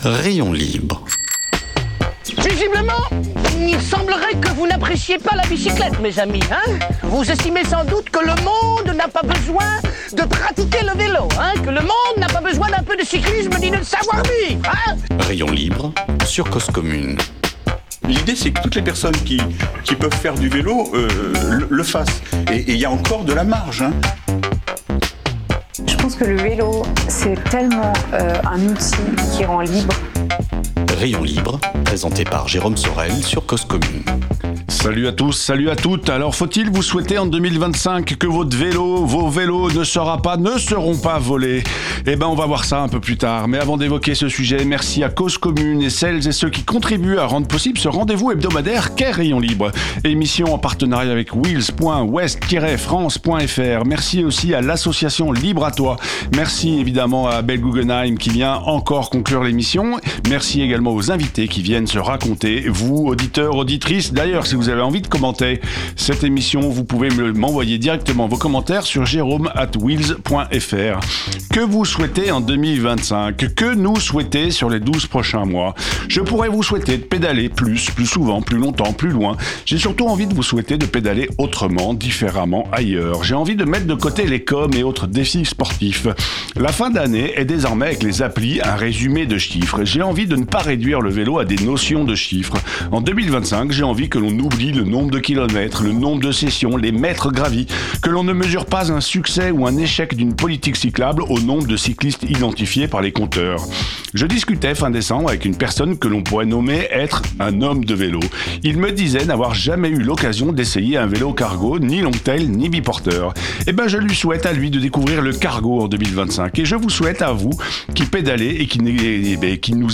Émission proposée en partenariat avec Weelz!